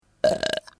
• Качество: высокое
6. Неприятная отрыжка